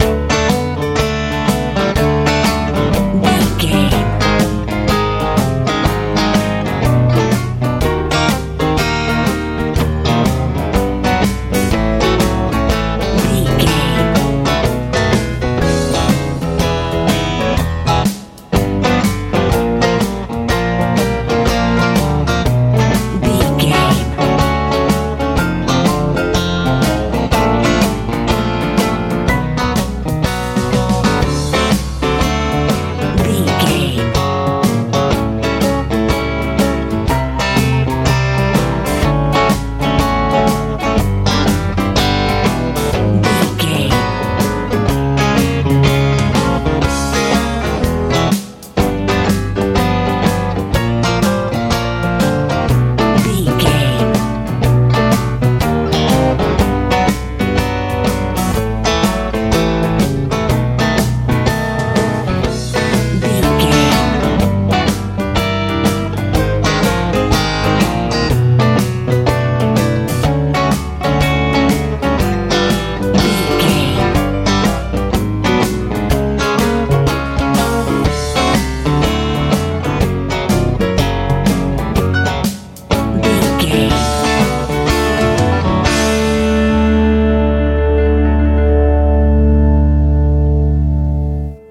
southern rock feel
Ionian/Major
D
calm
electric guitar
piano
bass guitar
drums
bright